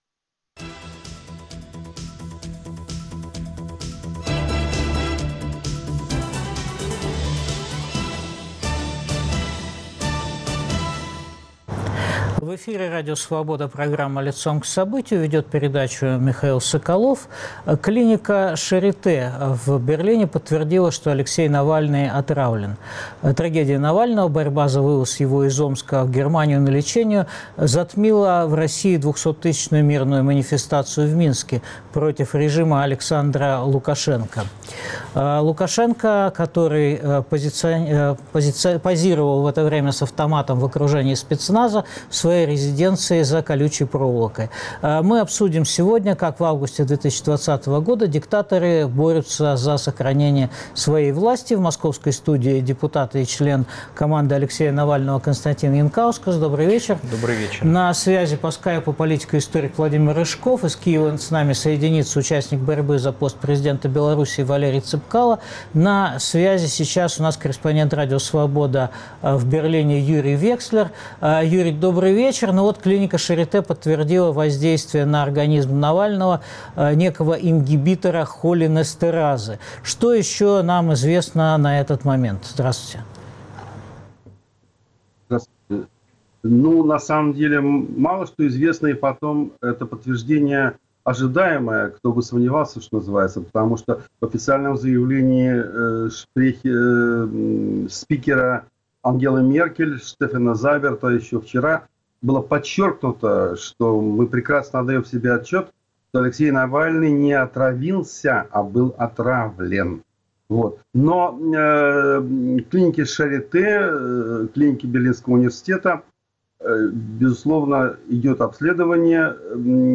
Как диктаторы борются за свою власть? Обсуждают Владимир Рыжков, Константин Янкаускас, Валерий Цепкало.